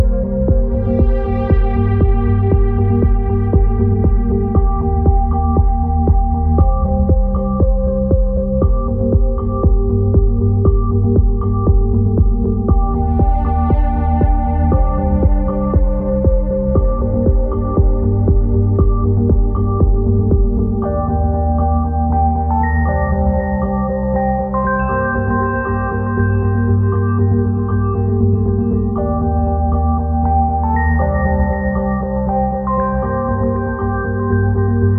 # Ambient